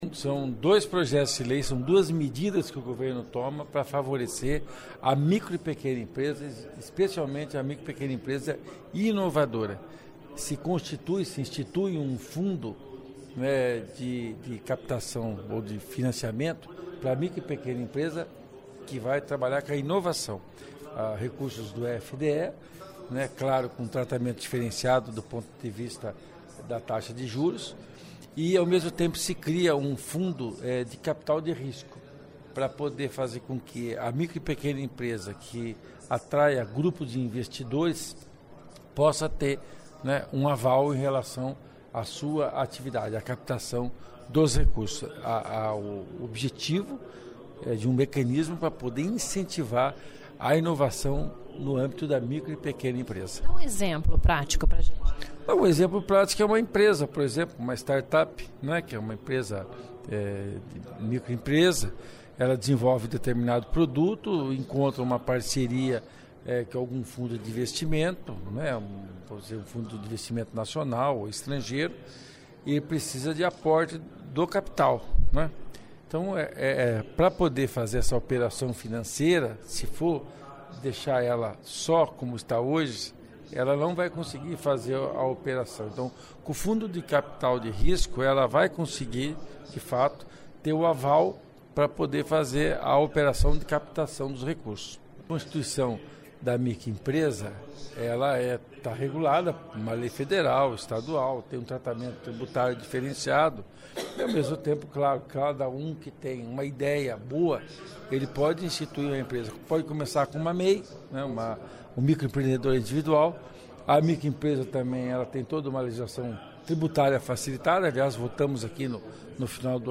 Ouça o que o lóder do Governo na Assembleia Legislativa, deputado Luiz Cláudio Romanelli (PSB) fala sobre o projeto e quem são as empresas que podem se beneficiar.
(Sonora)